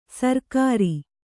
♪ sarkārai